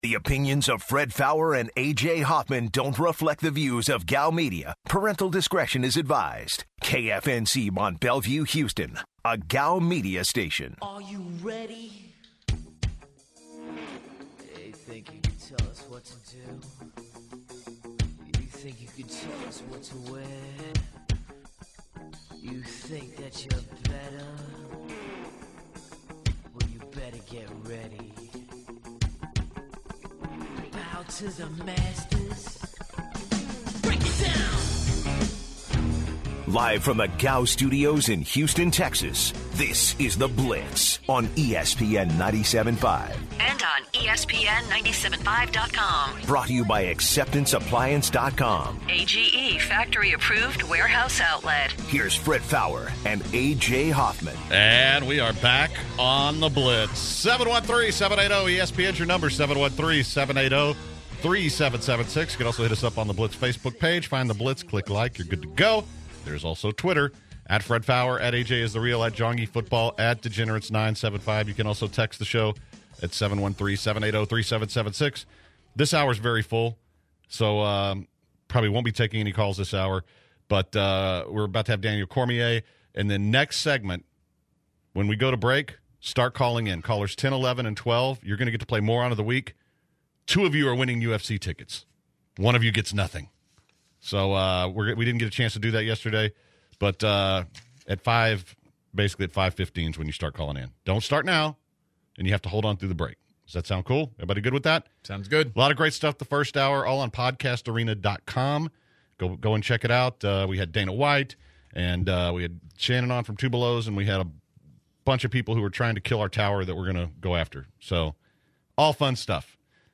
To open the second hour, the guys interview Daniel Cormier. How did he prepare for his next fight?